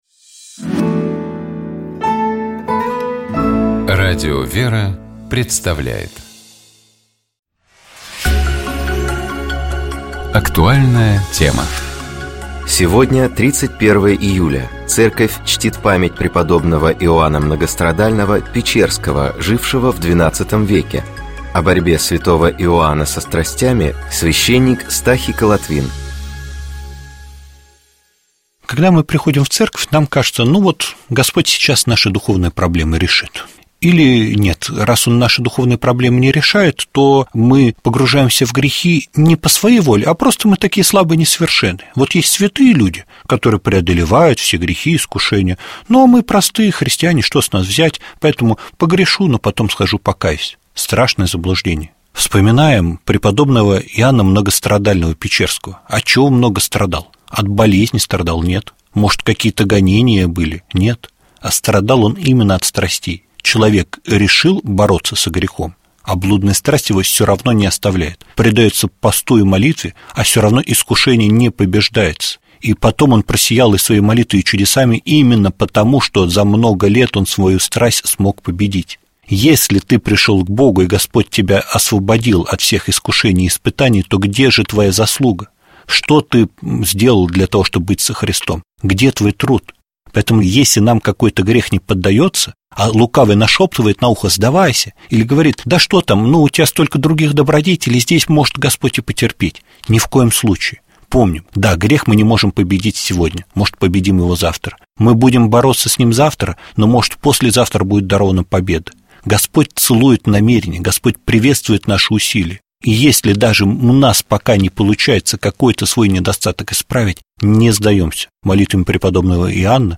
О борьбе святого Иоанна со страстями, — священник